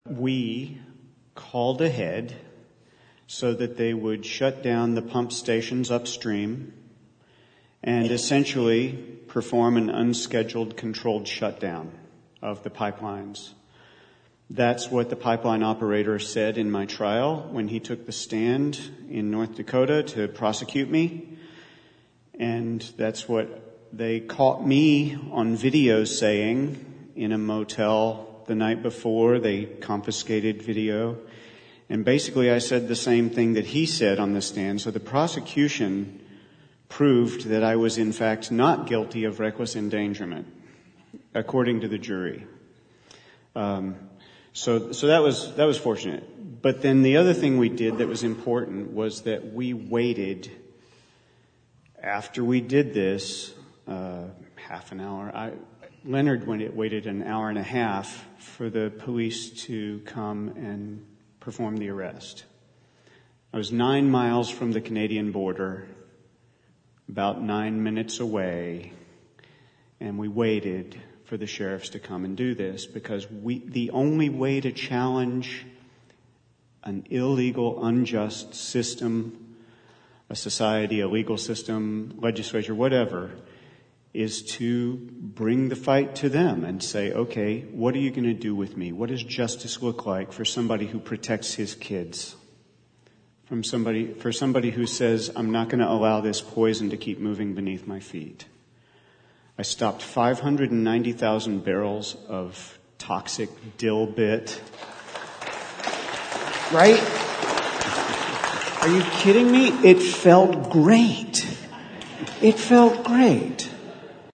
This interview was recorded during a live event held January 14, 2018 at the First Presbyterian Church of San Anselmo, California, and includes questions from the audience.